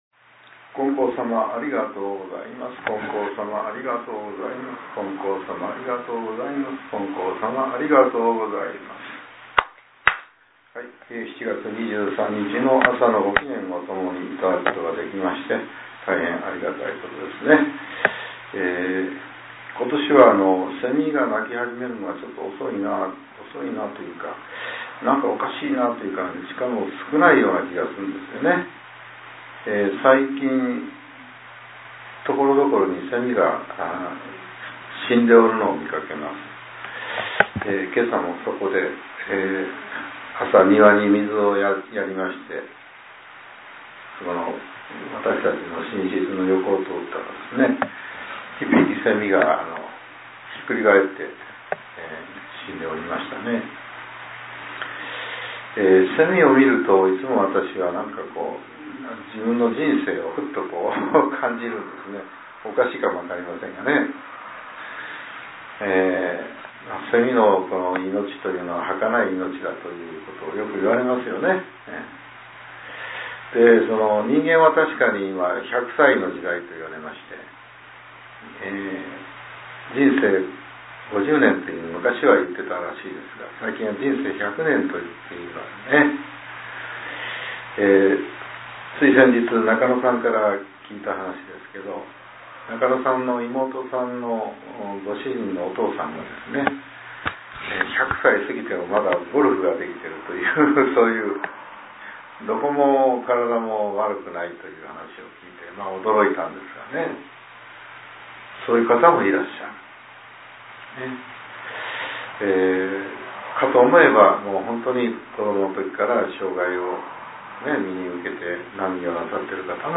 令和７年７月２３日（朝）のお話が、音声ブログとして更新させれています。